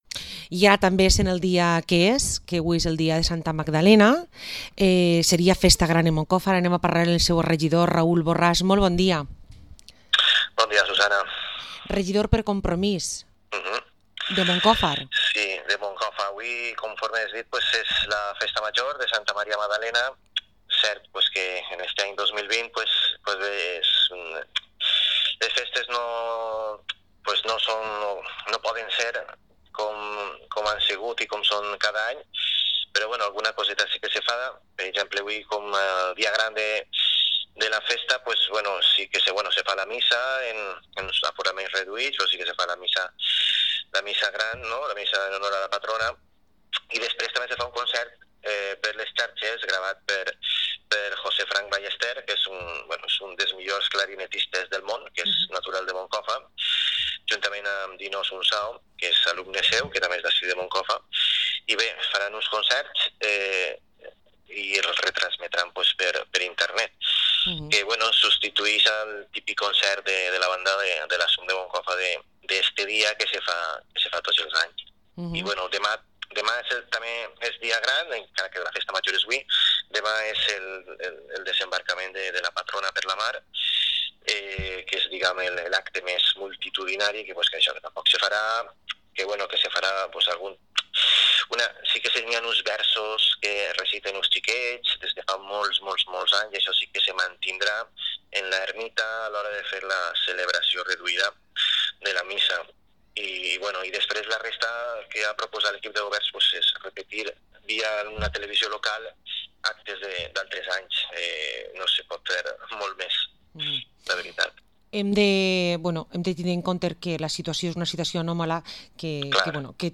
Entrevista al concejal de Compromís de Moncofa, Raúl Borrás